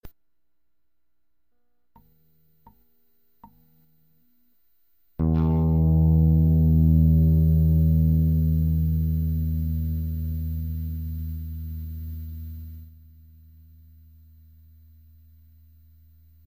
マグネチックは、大きな音が長く継続できてます。
・マグネチックのかなり上の方のはノイズですね
・弦をはじいた、ズン、ぼわーんという感じの弦の鳴りをよく表現できる。
2MAG_PIEZO_M.mp3